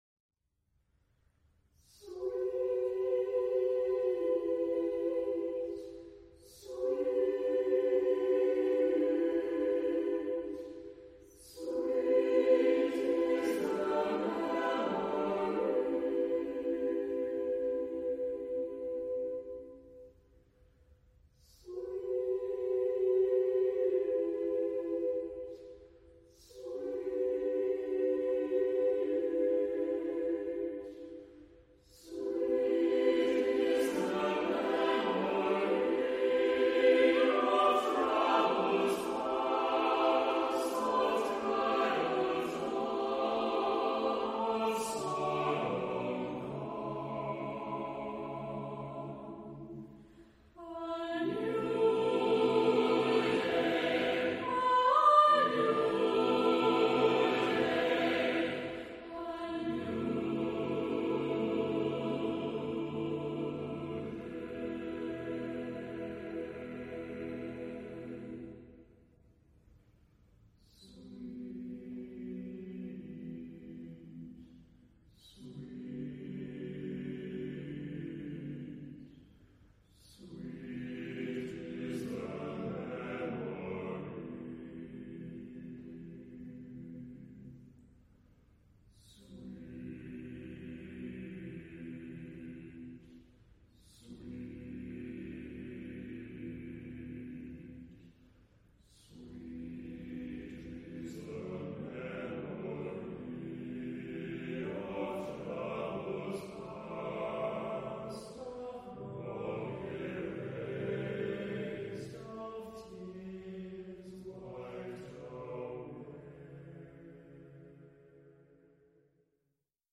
Epoque: 21st century
Genre-Style-Form: Secular ; Choir
Mood of the piece: slow
Type of Choir: SATB  (4 mixed voices )
Soloist(s): Tenor (1)  (1 soloist(s))
Tonality: E minor